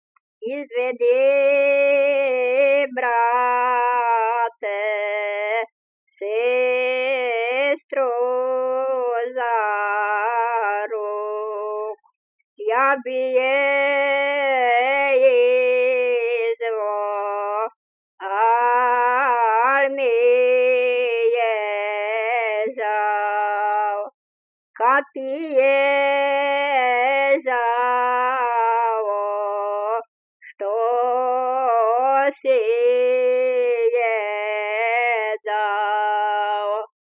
Dialect: D
Locality: Magyarcsanád/Čanad
Comment: Wedding song, sung when the bride’s brother leads the bride in front of the wedding procession.